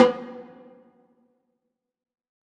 WTIMBALE H1O.wav